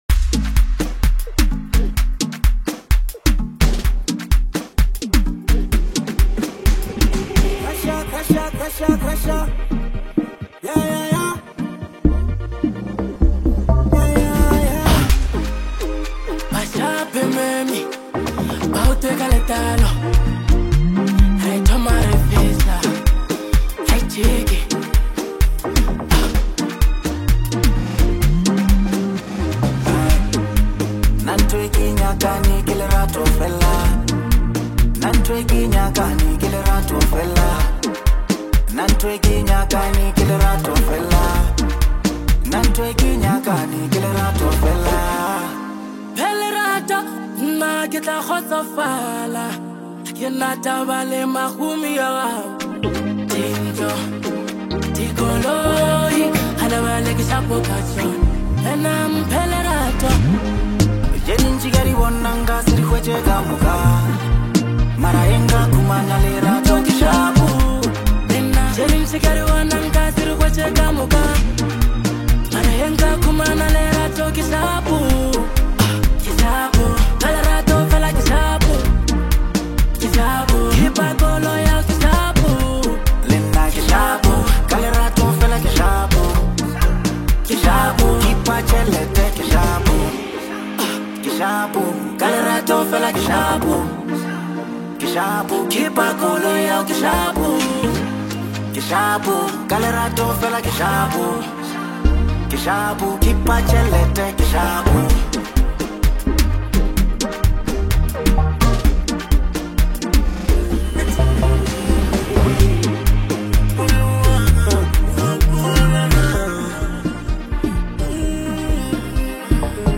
reflective genre-blending record
Lekompo